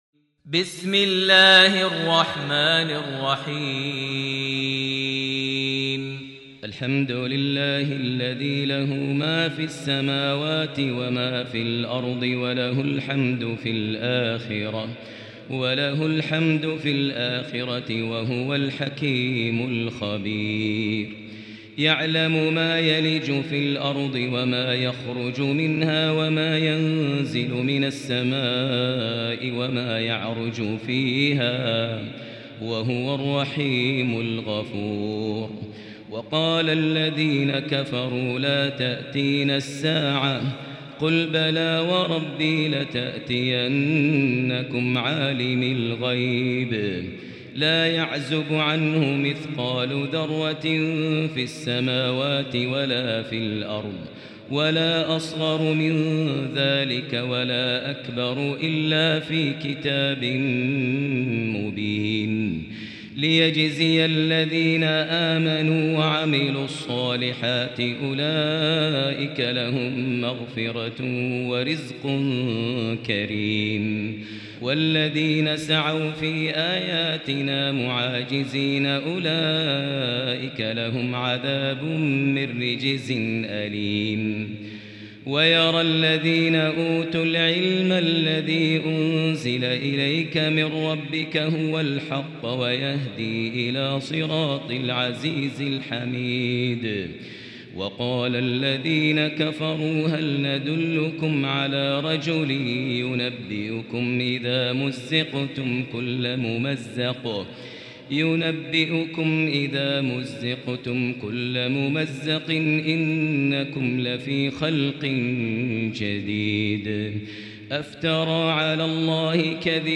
المكان: المسجد الحرام الشيخ: فضيلة الشيخ ماهر المعيقلي فضيلة الشيخ ماهر المعيقلي سبأ